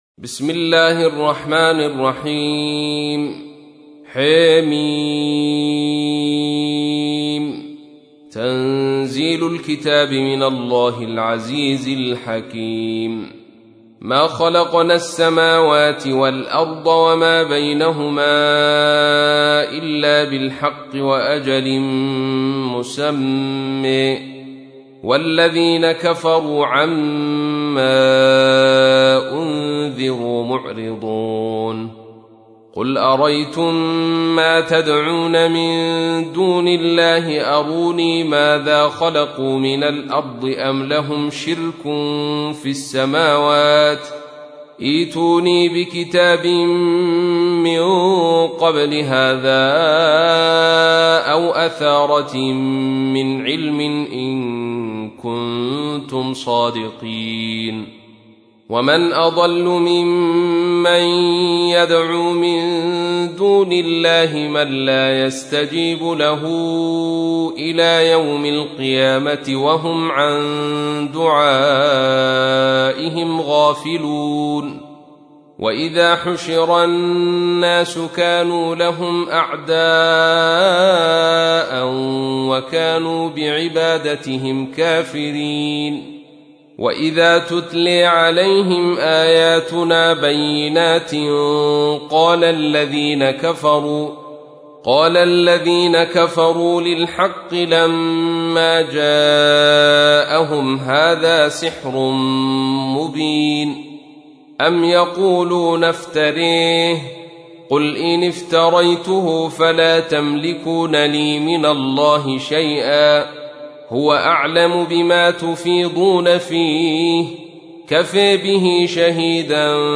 تحميل : 46. سورة الأحقاف / القارئ عبد الرشيد صوفي / القرآن الكريم / موقع يا حسين